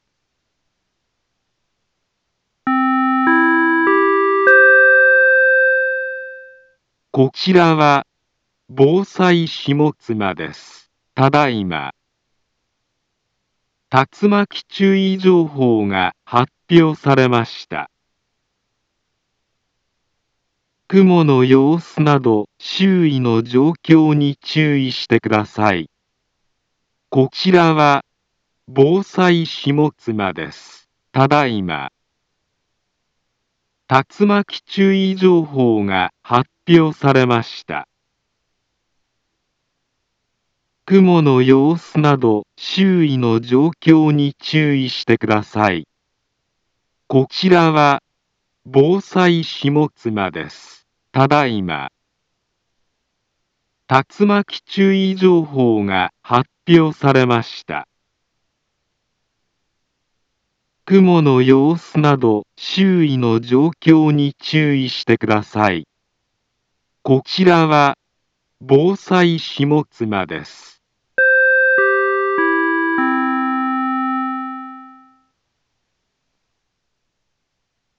Back Home Ｊアラート情報 音声放送 再生 災害情報 カテゴリ：J-ALERT 登録日時：2024-07-20 14:24:33 インフォメーション：茨城県北部、南部は、竜巻などの激しい突風が発生しやすい気象状況になっています。